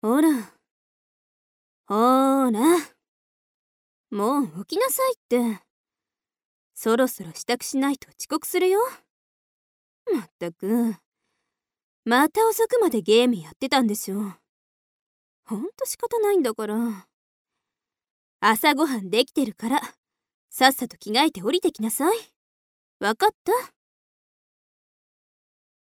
アトリエピーチのサンプルボイス一覧および紹介
セクシー